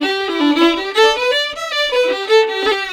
Index of /90_sSampleCDs/USB Soundscan vol.42 - Celtic Flavours [AKAI] 1CD/Partition E/04-VIOLIN160